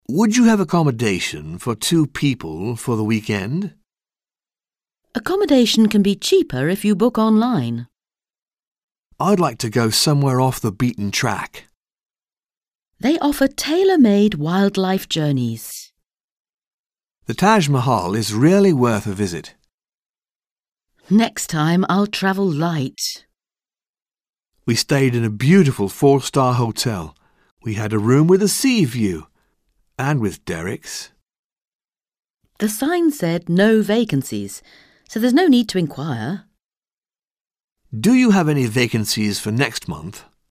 Un peu de conversation - Séjourner et visiter